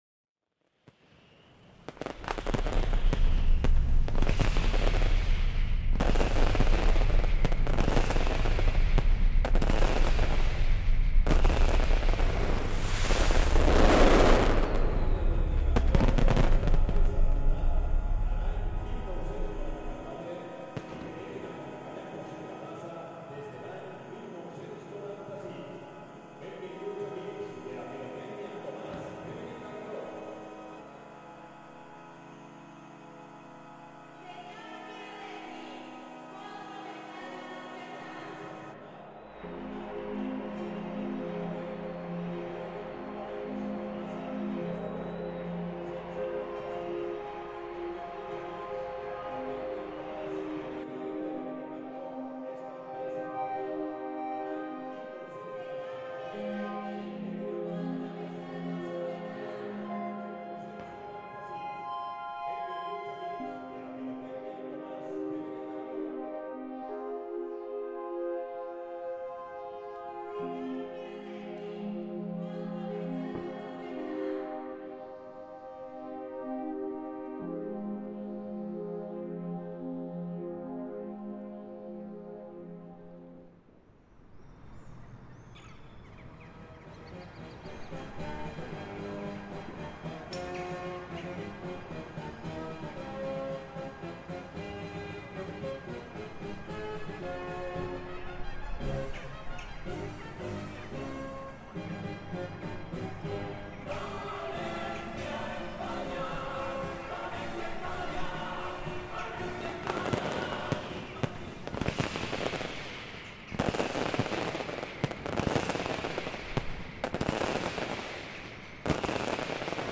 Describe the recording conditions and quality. Valencia Mascleta celebration reimagined